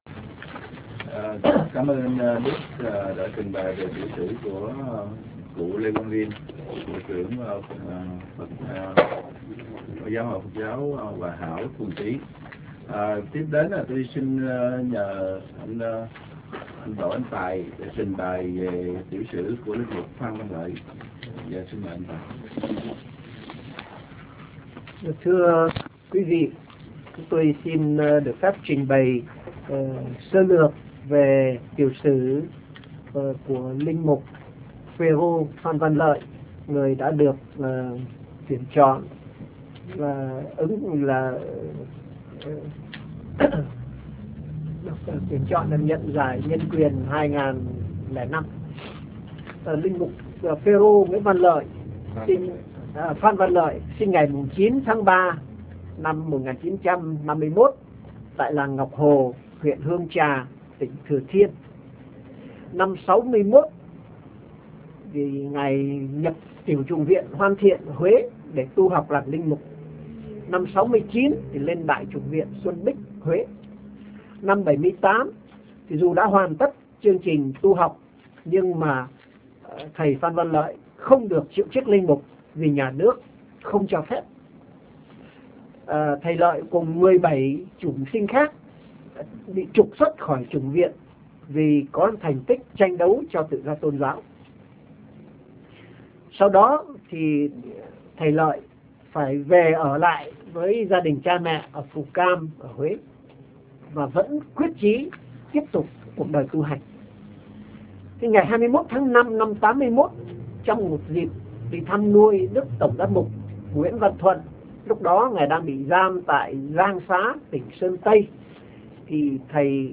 MẠNG LƯỚI NH�N QUYỀN HỌP B�O